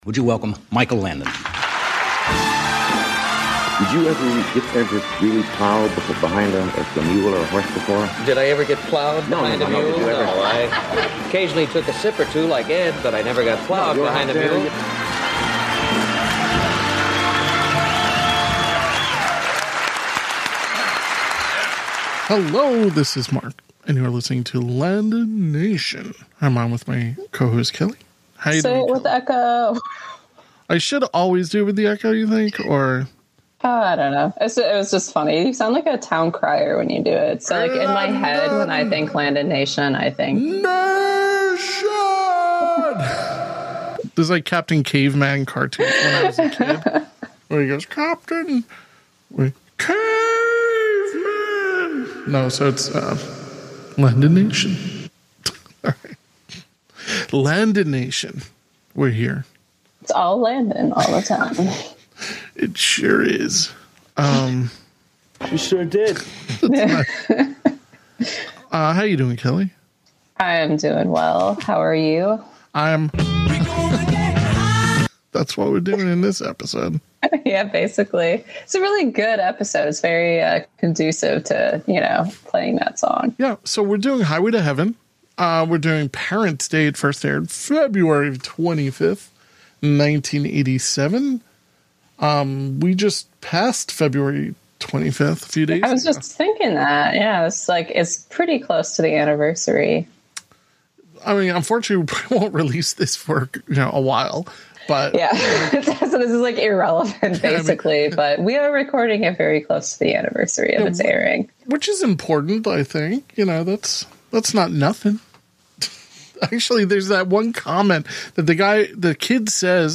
We play tons of amazing clips and have a rip roaring time!